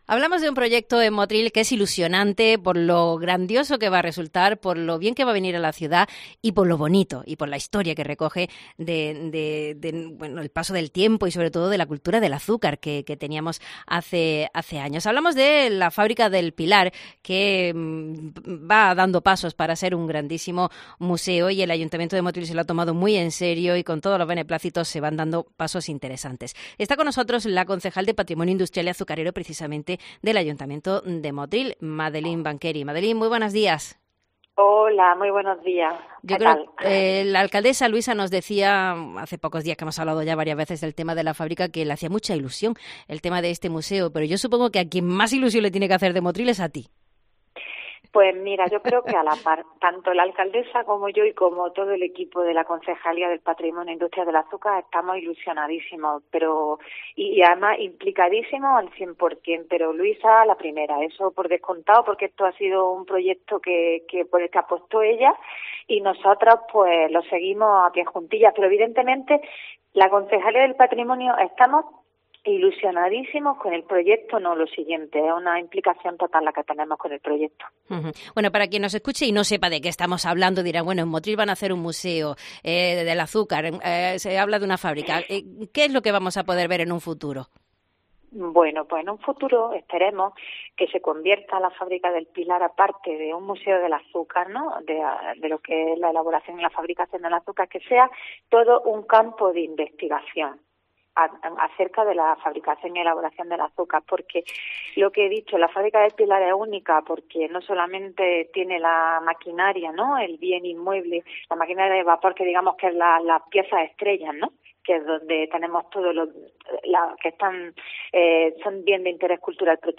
Magdalena Banqueri, concejala de Patrimonio Industrial y Azucarero del Ayuntamiento de Motril, nos cuenta en COPE cómo es este tesoro y los pasos que se están dando para la consecución del Museo.